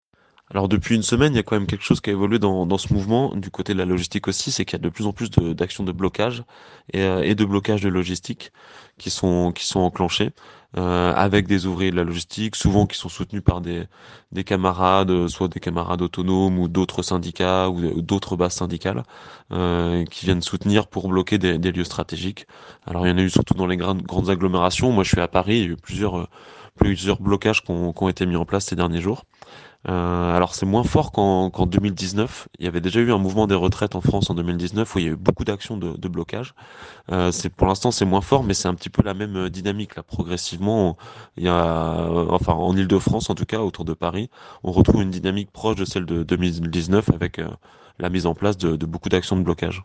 Su blocchi, logistica e riflessioni dalla base della CGT, ecco i contributi tradotti nella corrispondenza da Parigi: